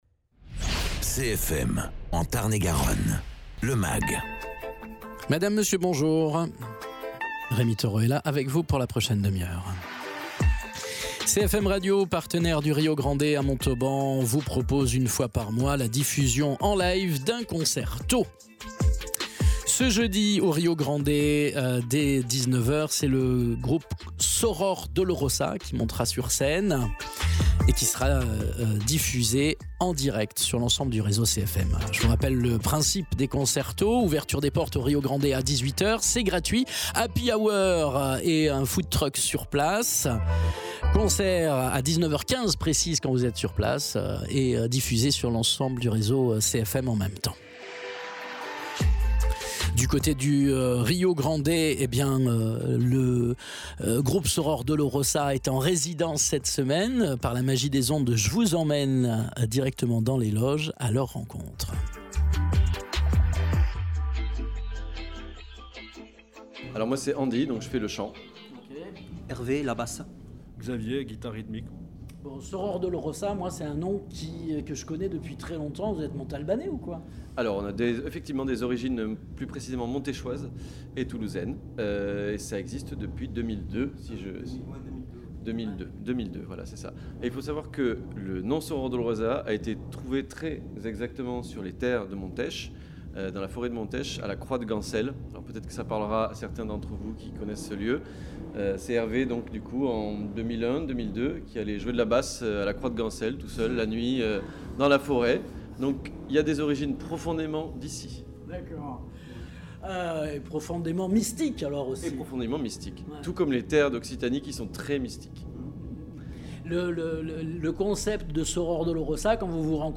Rencontre avec trois musiciens de Soror Dolorosa lors de leur résidence au Rio-Grande à Montauban pour annoncer le concert tôt retransmis en direct sur CFM le jeudi 9 février à 19h